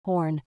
horn.mp3